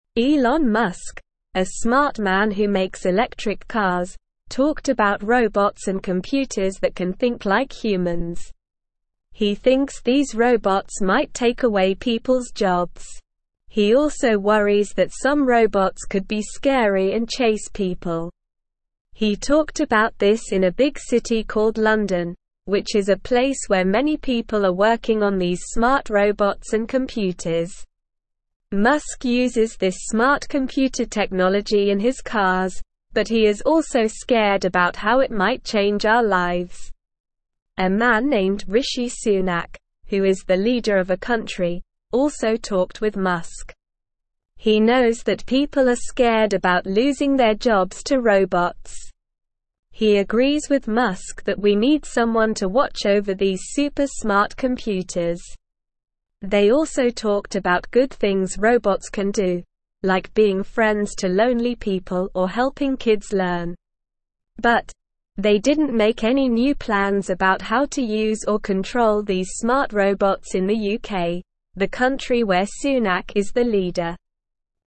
Slow
English-Newsroom-Beginner-SLOW-Reading-Elon-Musk-and-Rishi-Sunak-Discuss-Smart-Robots.mp3